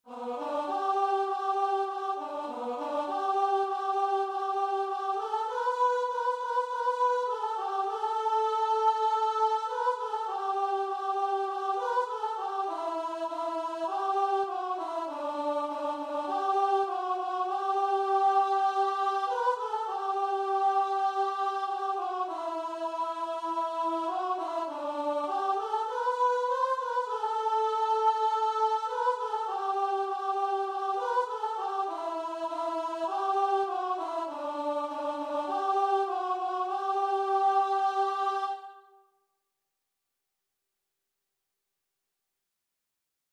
Christian Christian Guitar and Vocal Sheet Music Are You Washed in the Blood?
Free Sheet music for Guitar and Vocal
4/4 (View more 4/4 Music)
G major (Sounding Pitch) (View more G major Music for Guitar and Vocal )
Classical (View more Classical Guitar and Vocal Music)